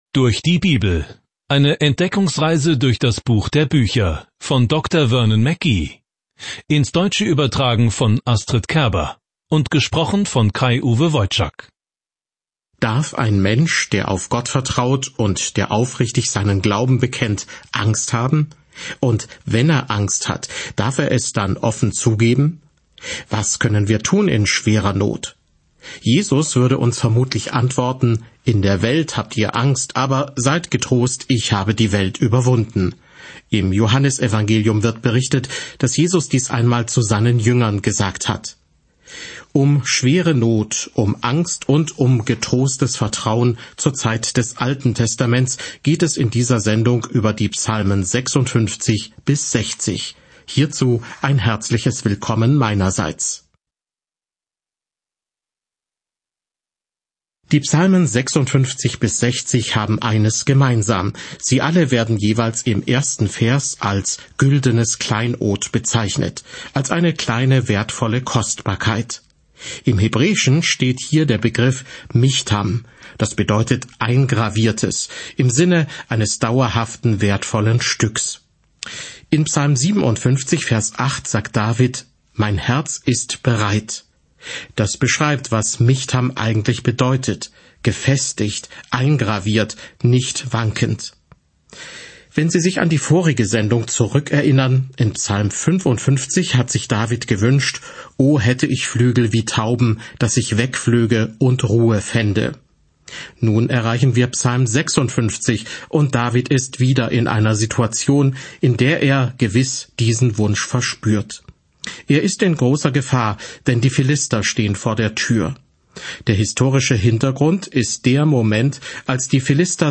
Reisen Sie täglich durch die Psalmen, während Sie sich die Audiostudie anhören und ausgewählte Verse aus Gottes Wort lesen.